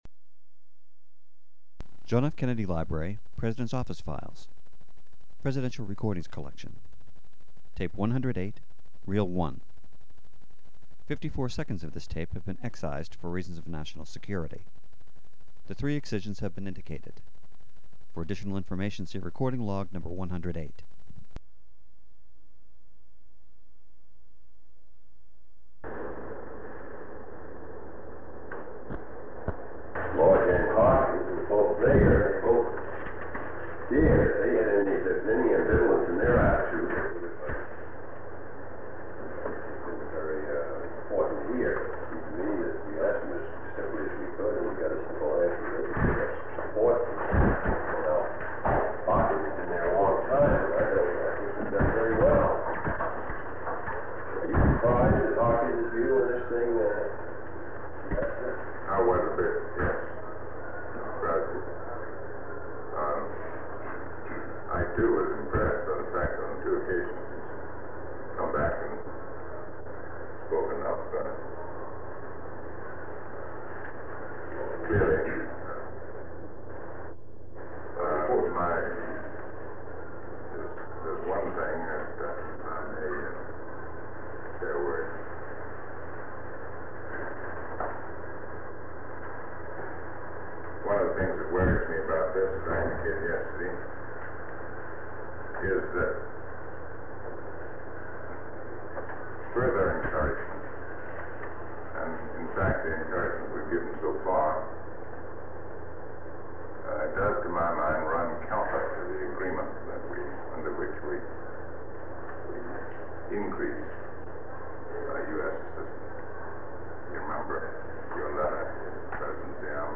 Sound recording of part of a meeting held on August 28, 1963, between President John F. Kennedy, former United States Ambassador to Vietnam Frederick Nolting, Chairman of the Joint Chiefs of Staff General Maxwell Taylor, Under Secretary of State George Ball, Under Secretary of State for Political Affairs W. Averell Harriman, Deputy Secretary of Defense Roswell Gilpatric, Director of the Central Intelligence Agency's (CIA) Far Eastern Division William Colby, Deputy Director of the CIA General Mar
Secret White House Tapes | John F. Kennedy Presidency Meetings: Tape 108/A43.